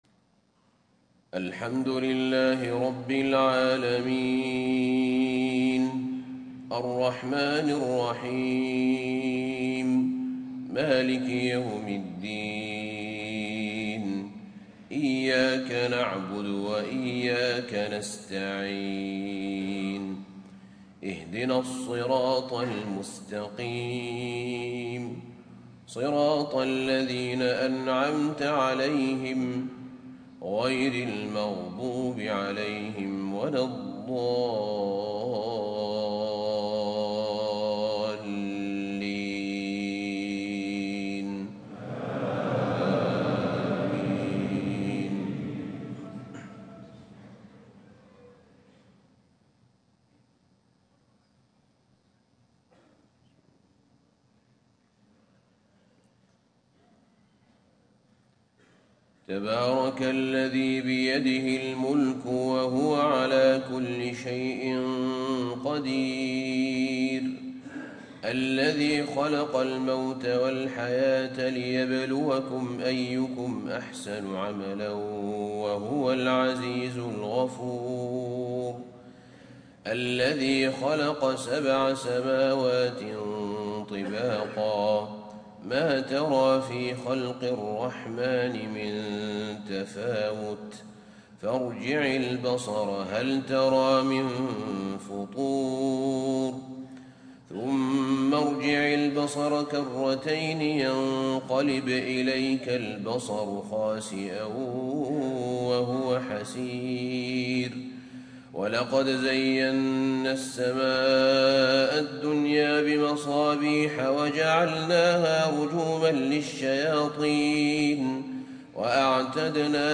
صلاة الفجر 23 صفر 1437هـ سورة الملك > 1437 🕌 > الفروض - تلاوات الحرمين